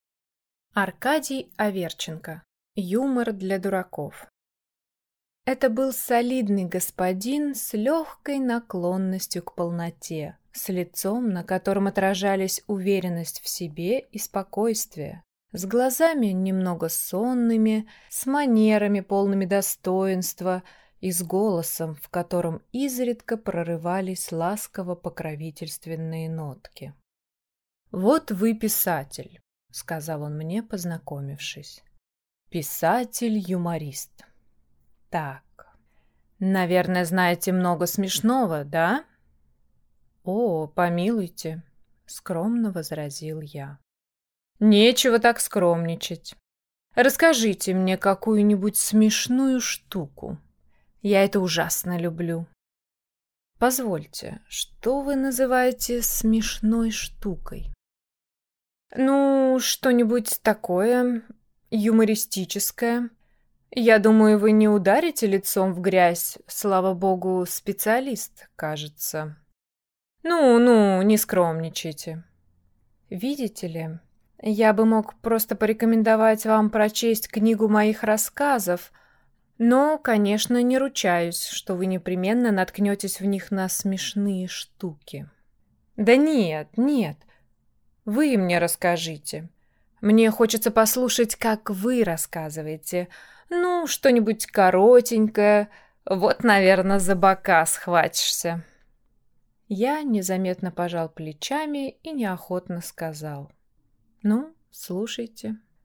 Аудиокнига Юмор для дураков | Библиотека аудиокниг